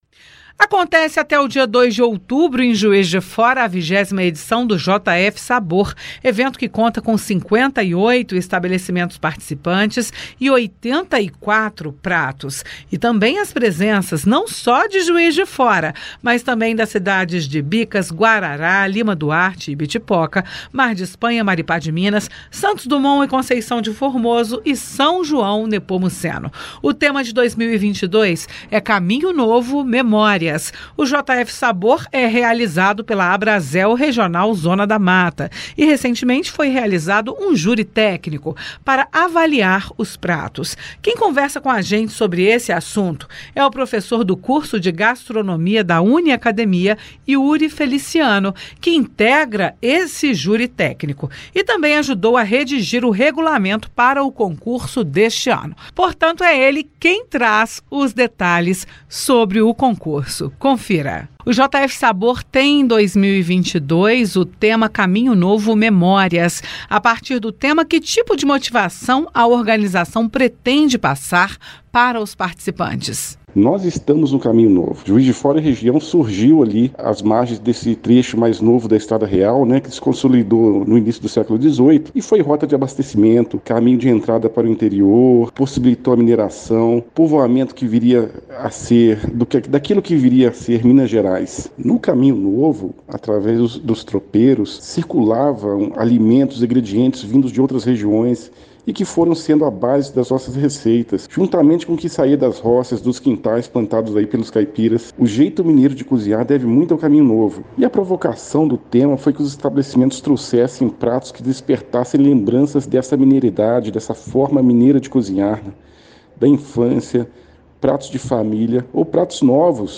Categorias: Entrevista